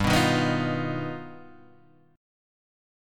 G+7 chord